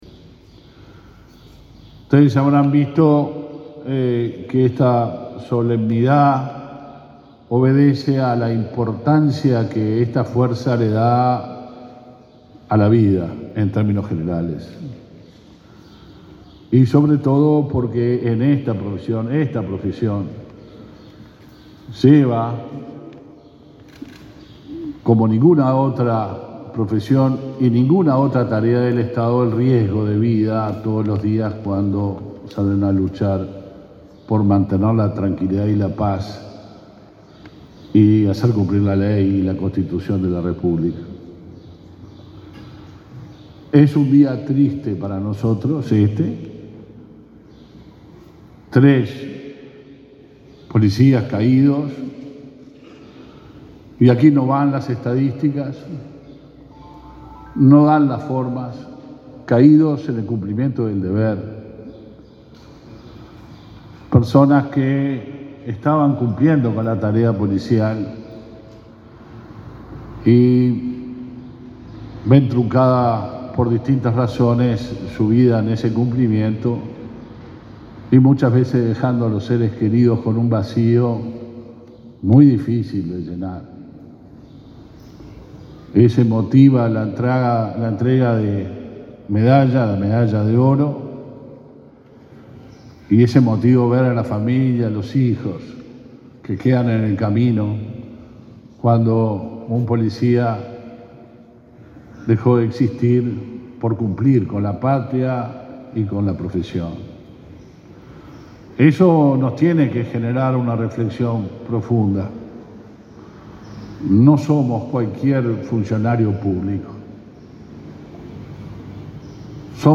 Palabras del ministro del Interior, Luis Alberto Heber
Palabras del ministro del Interior, Luis Alberto Heber 21/11/2022 Compartir Facebook X Copiar enlace WhatsApp LinkedIn El ministro del Interior, Luis Alberto Heber, encabezó la ceremonia de reconocimiento en el Día del Policía Caído en Cumplimiento del Deber, realizada este lunes 21 en la plaza de la Policía, en Montevideo.